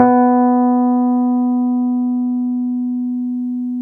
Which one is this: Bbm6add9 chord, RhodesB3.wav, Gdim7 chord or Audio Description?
RhodesB3.wav